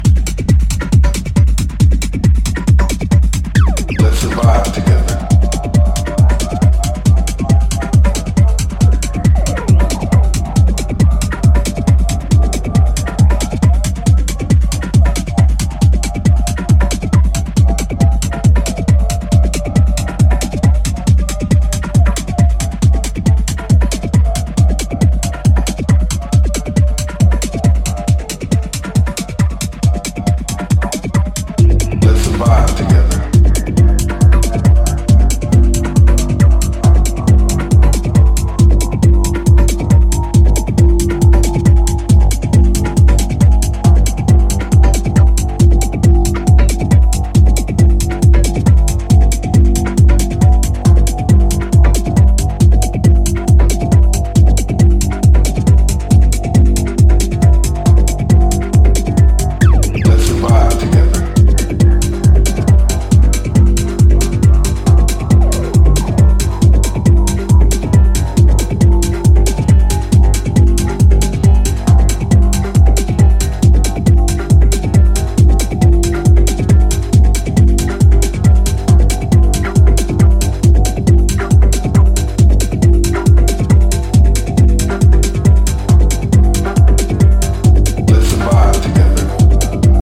本作ではよりアブストラクトなテクノの領域に分け入っています。